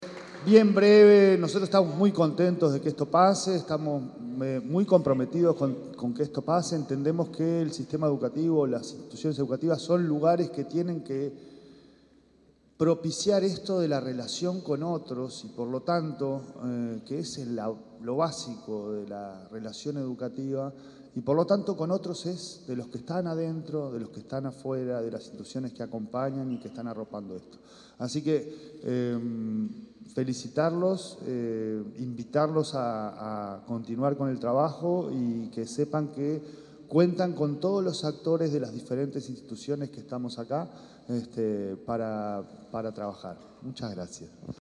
Palabras del presidente de la ANEP, Pablo Caggiani
Palabras del presidente de la ANEP, Pablo Caggiani 30/10/2025 Compartir Facebook X Copiar enlace WhatsApp LinkedIn El presidente de la Administración Nacional de Educación Pública, Pablo Caggiani, se expresó durante la presentación de la Comisión de Convivencia y Participación.